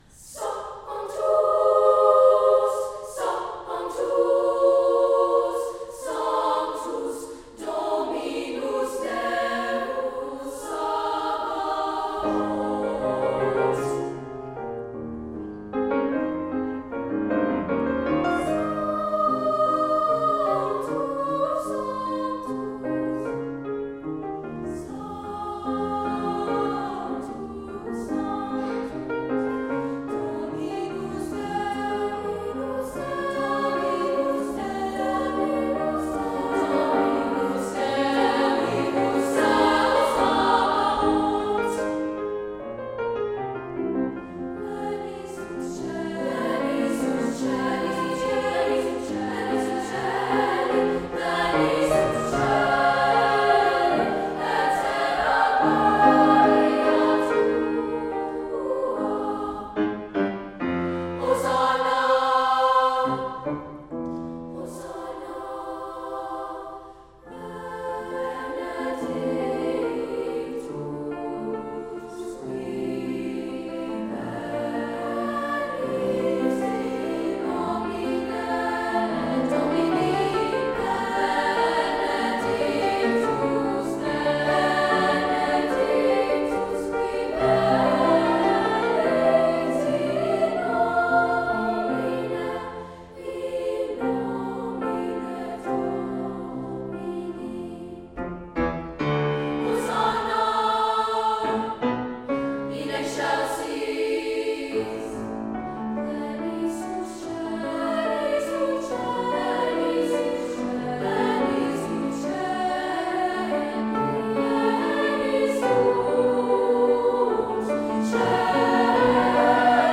Voicing: SSA
Instrumentation: piano